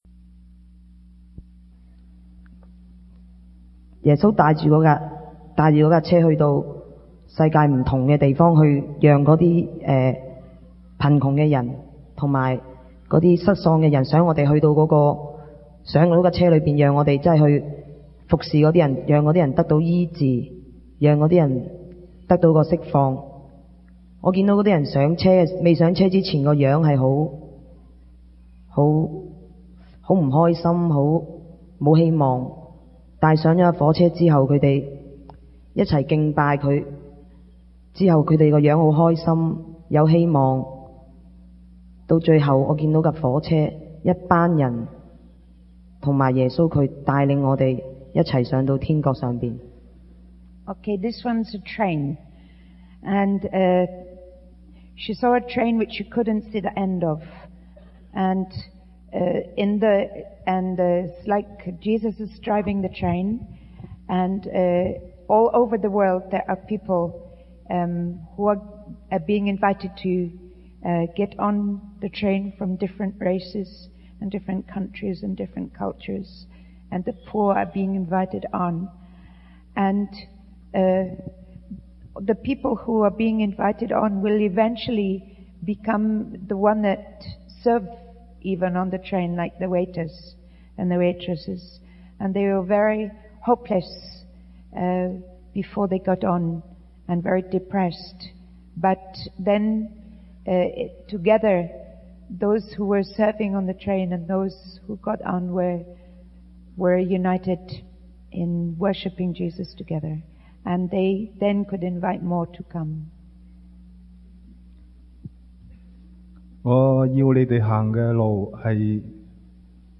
Preacher: Jackie Pullinger | Series: Fragrance of Justice
Please note that as these sermons were recorded on tape, some small segments may be missing.